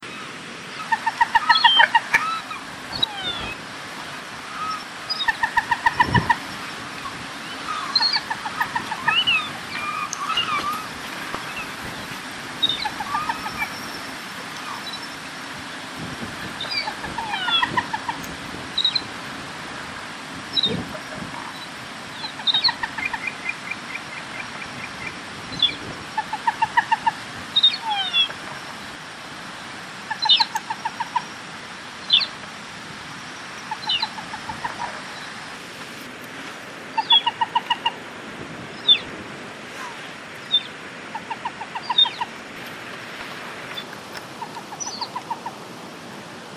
NOMBRE CIENTÍFICO Porphyriops (Gallinula) melanops melanops
Polla pintada.wav